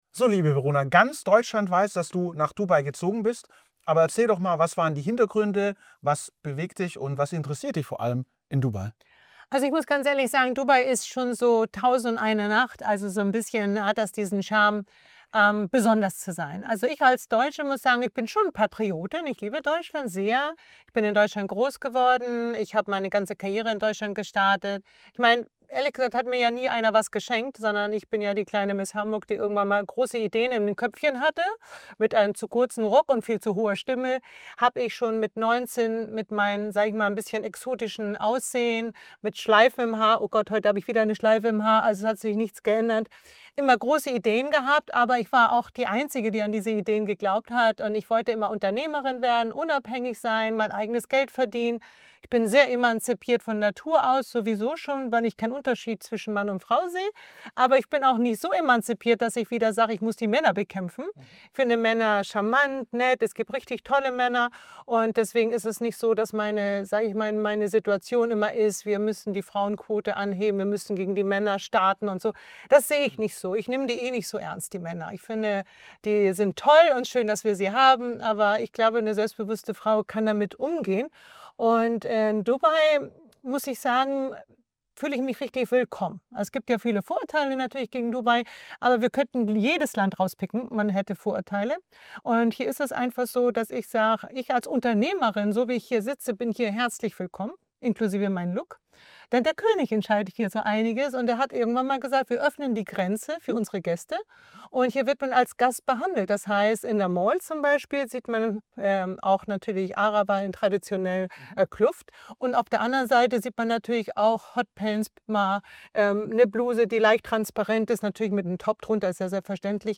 ein exklusives Gespräch mit Verona Pooth, die über ihren großen Schritt ins Ausland spricht. Sie teilt ihre Beweggründe, ihre persönlichen Erfahrungen und was das für sie bedeutet.